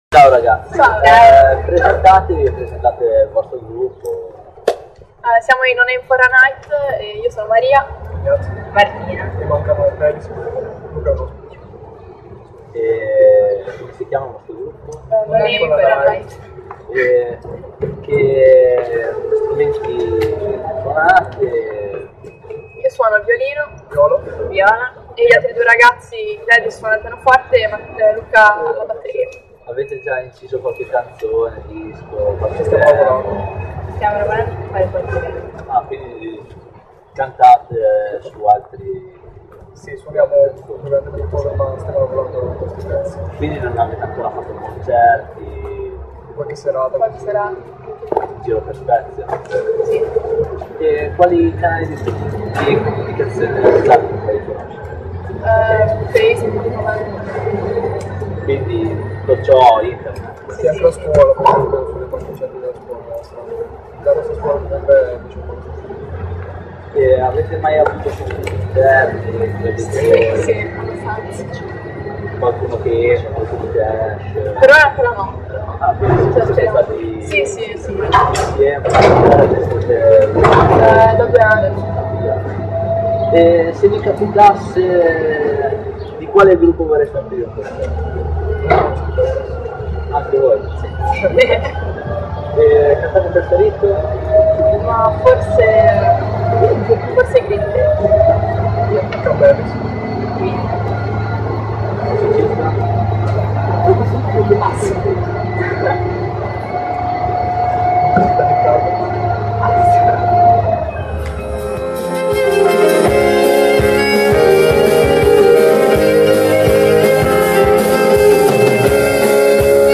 Intervista a No Name for a Night